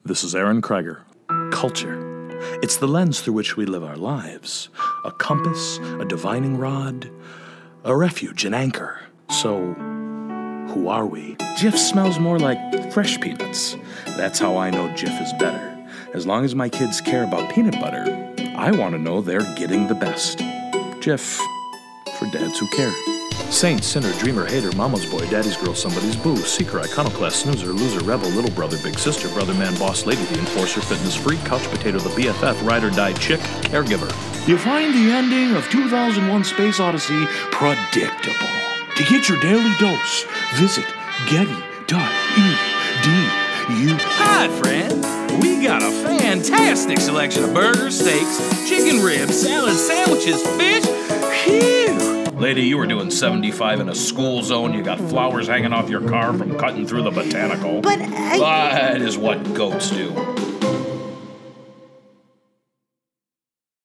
My demo reel
Irish, British, French
Young Adult
Middle Aged